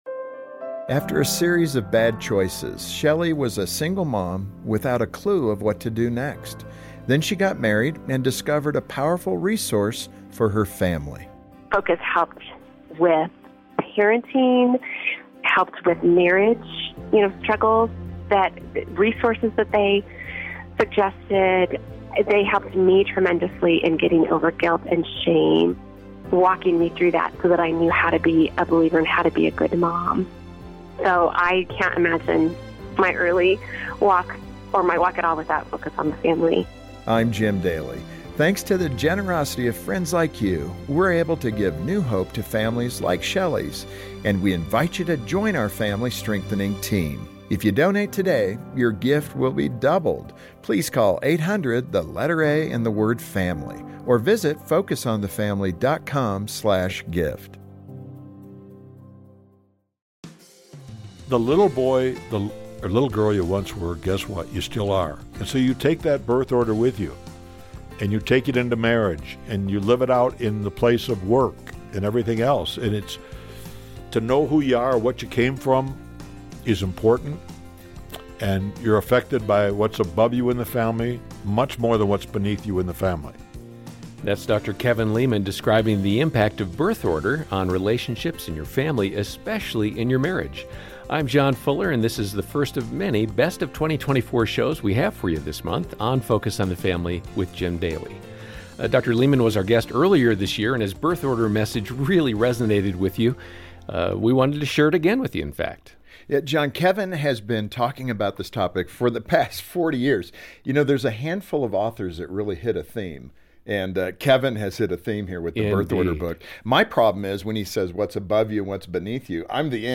At the end of this engaging conversation, we open the floor to the audience so that they might ask Dr. Leman specific questions regarding the subject matter.